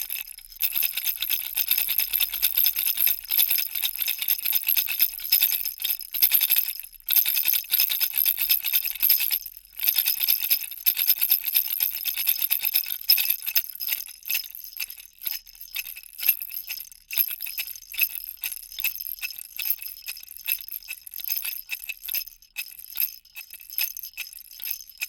В подборке собраны варианты с разным тембром и интенсивностью звучания: от тихого шуршания до громкого треска.
Деревянная погремушка для малыша